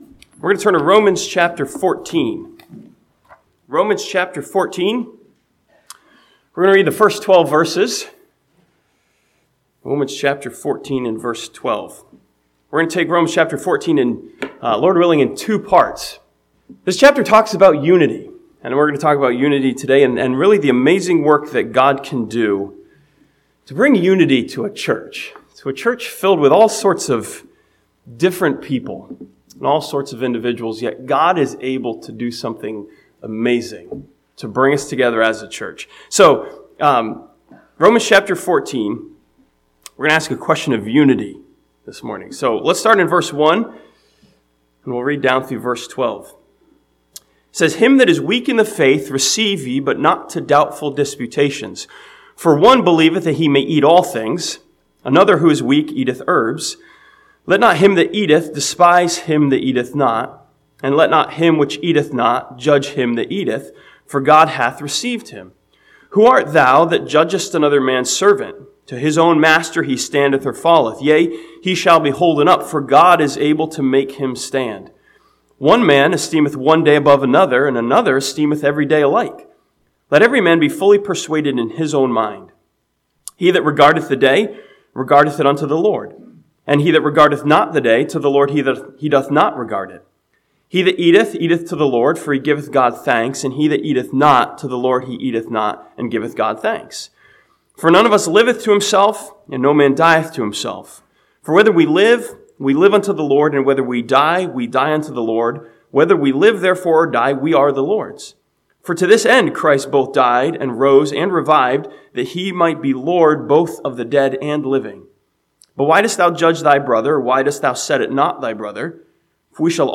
This sermon from Romans chapter 14 challenges the church by asking the question, 'how can individuals have unity?'